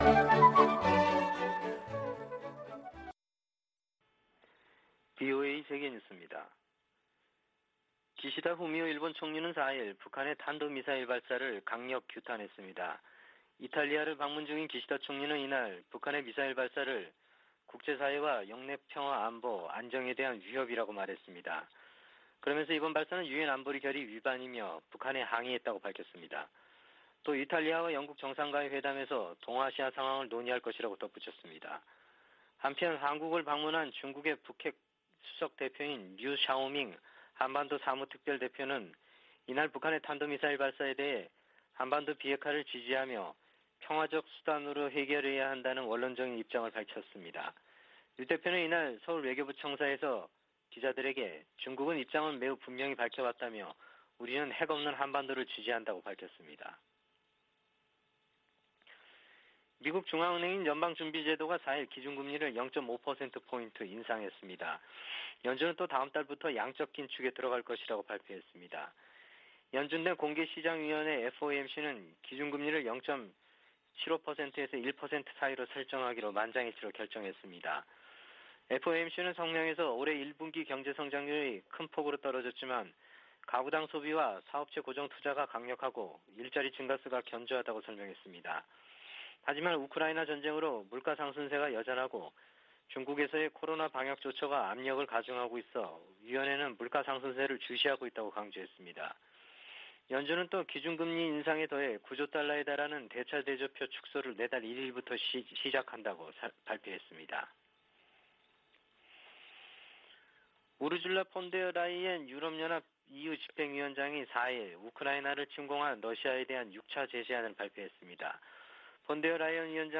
VOA 한국어 아침 뉴스 프로그램 '워싱턴 뉴스 광장' 2022년 5월 5일 방송입니다. 북한이 한국 새 정부 출범을 앞두고 또 다시 탄도미사일을 발사했습니다. 미국은 북한의 대륙간탄도미사일(ICBM) 도발에 대한 새 유엔 안보리 결의를 위해 논의하고 있다고 밝혔습니다.